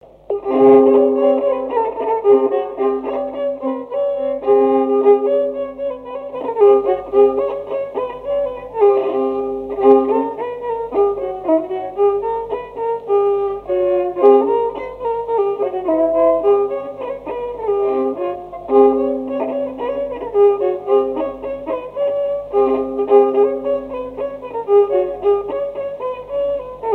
Couplets à danser
branle : courante, maraîchine
répertoire d'un violoneux
Pièce musicale inédite